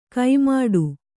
♪ kai māḍu